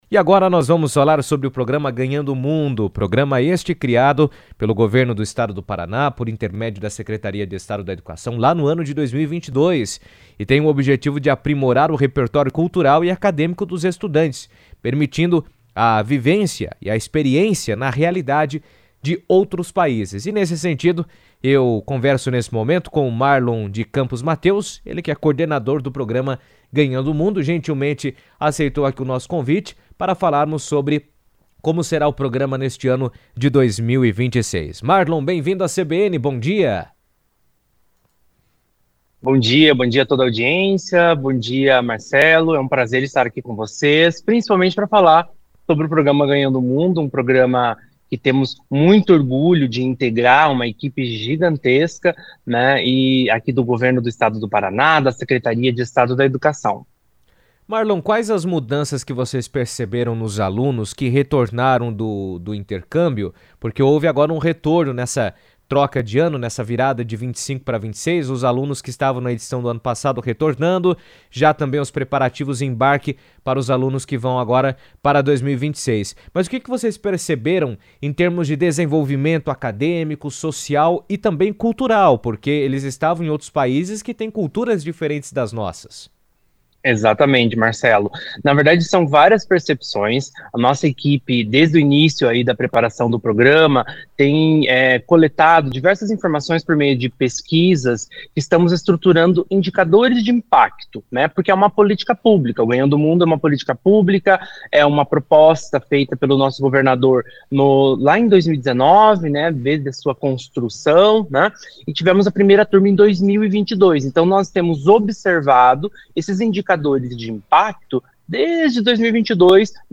O programa Ganhando o Mundo registrou sua maior edição em 2025 e já projeta superar esses números em 2026. Em entrevista à CBN